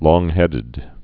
(lônghĕdĭd, lŏng-)